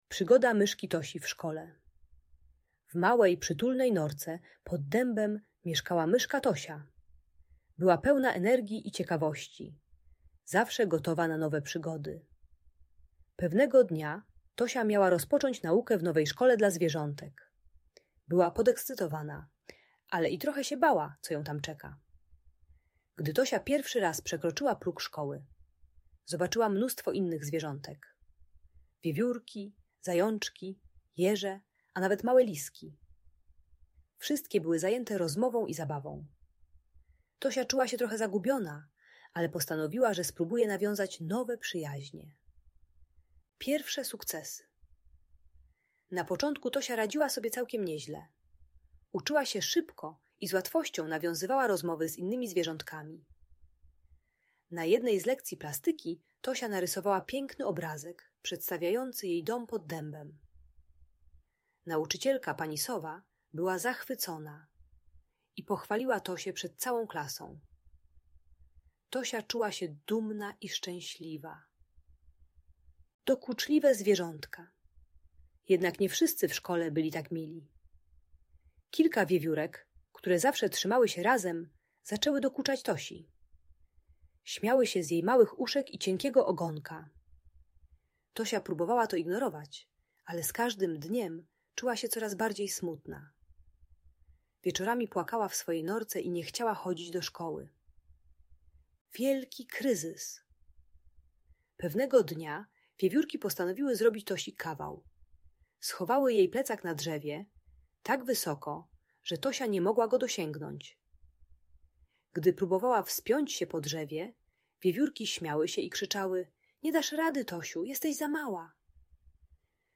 Przygoda Myszki Tosi - Bunt i wybuchy złości | Audiobajka